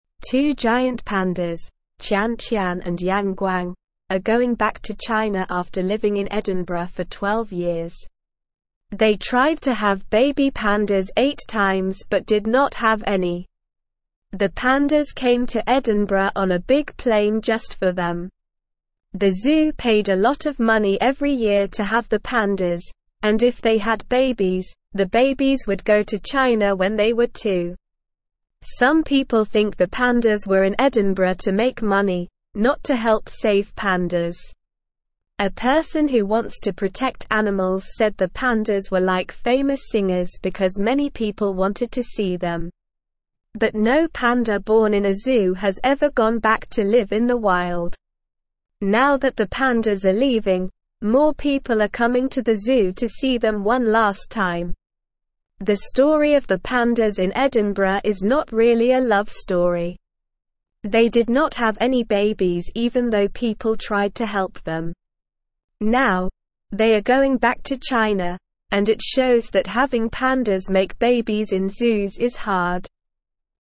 Slow
English-Newsroom-Lower-Intermediate-SLOW-Reading-Big-Bears-Tian-Tian-and-Yang-Guang-Go-Home.mp3